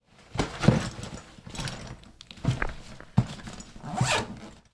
action_tentpack_0.ogg